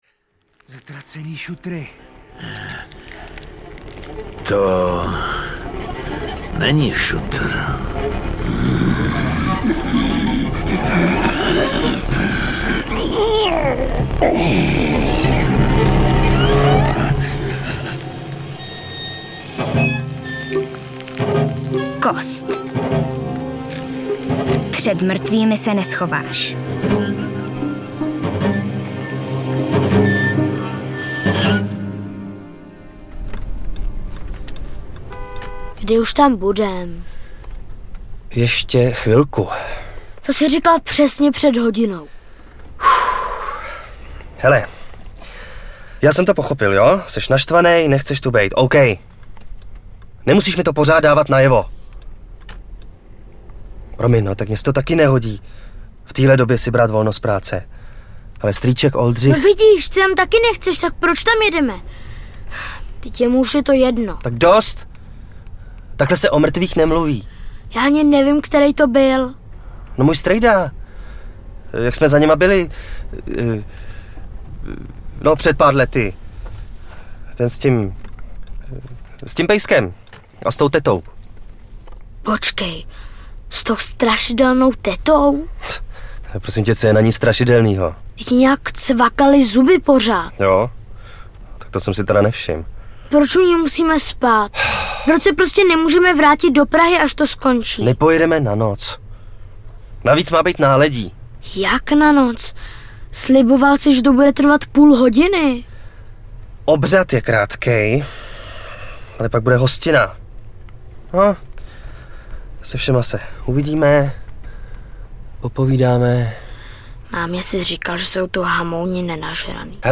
Kost audiokniha
Mysteriózní rozhlasový šestidílný seriál vás zavede na jeden obyčejný vesnický pohřeb, který bude mít nečekanou dohru. Jak si udobřit naštvaného ducha a uklidnit splašené svědomí?
Ukázka z knihy